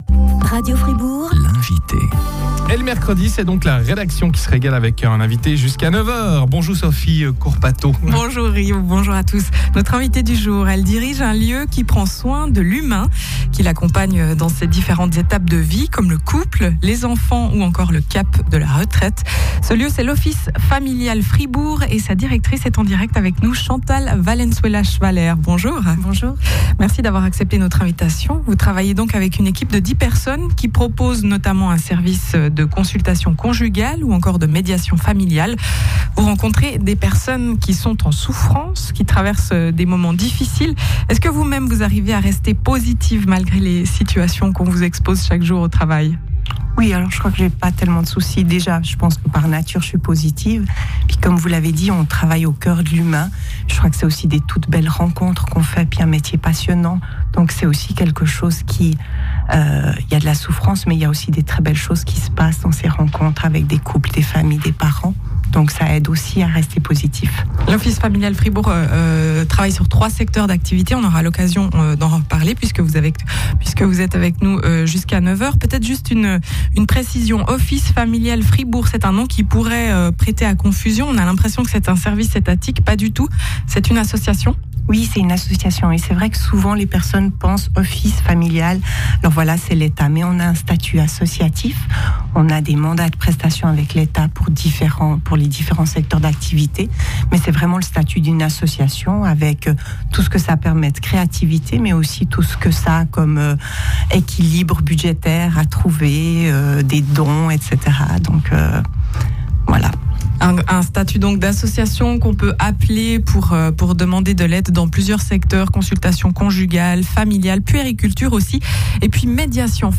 Radio Fribourg - Invitée du mercredi